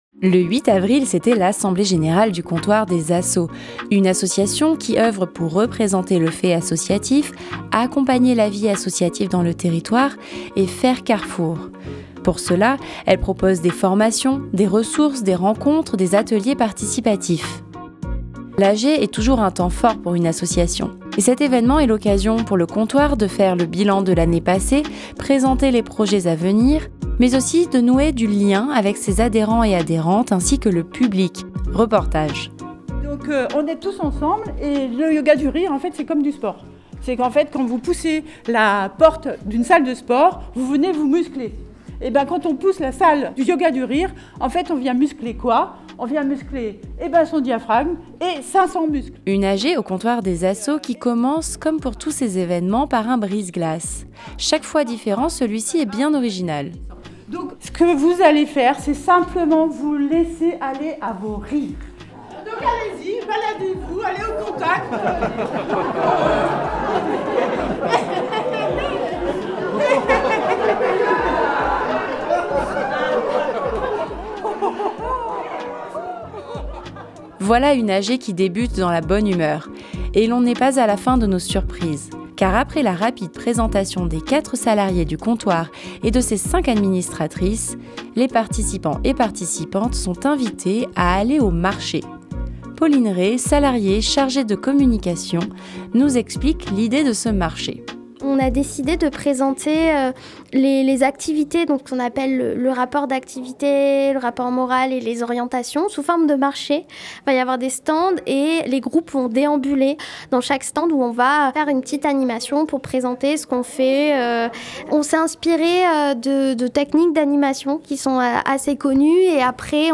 Le 8 avril, c'était l'Assemblée Générale du Comptoir des assos, association qui oeuvre pour représenter le fait associatif, accompagner la vie associative dans le territoire, faire carrefour.
Reportage AG Comptoir des Assos 2025.mp3 (24.88 Mo)